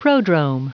Prononciation du mot prodrome en anglais (fichier audio)
Prononciation du mot : prodrome